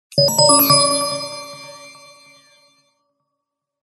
Звон бонусного колокольчика